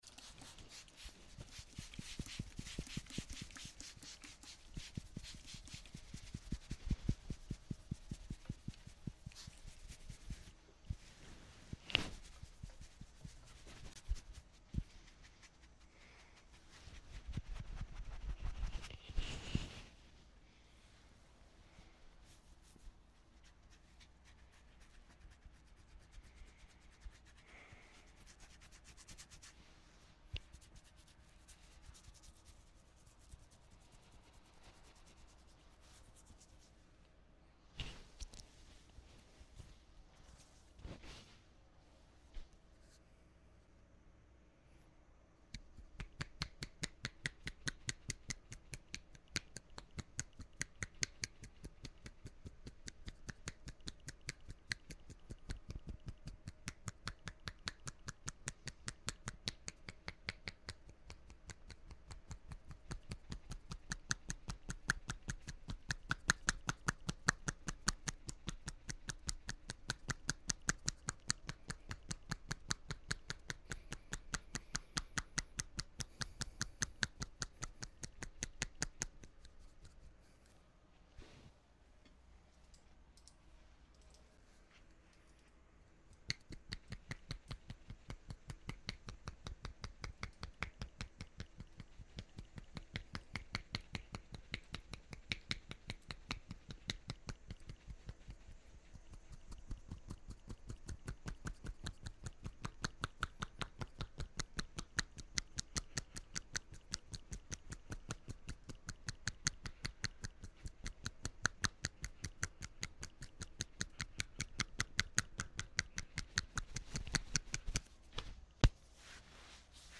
Sounds so soothing, you will get the tingles!